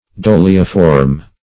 Search Result for " dolioform" : The Collaborative International Dictionary of English v.0.48: dolioform \do"li*o*form\, a. [L. dolium large jar + -form.]